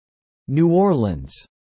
英語名New Orleans（英語発音: [njuː ˈɔːrliənz]）およびフランス語名La Nouvelle-Orléans（フランス語発音: [lanuvɛlɔrleɑ̃] ）は、「新オルレアン」という意味でルイ15世の摂政オルレアン公フィリップ2世に因む。